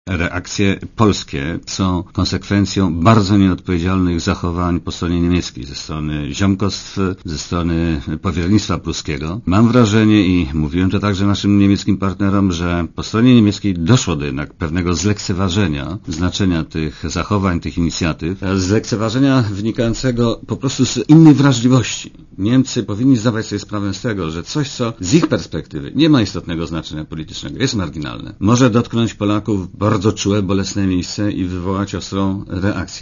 Posłuchaj komentarza Włodzimierza Cimoszewicza
Zdaniem Cimoszewicza, to parlamentarzyści zlekceważyli stanowisko rządu w sprawie reparacji. Rząd musi się trzymać faktów, rząd musi się trzymać prawa, a nie - nawet zrozumiałych - emocji - powiedział minister w Radiu Zet.